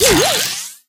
tick_atk_01.ogg